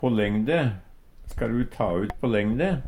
på lengde - Numedalsmål (en-US)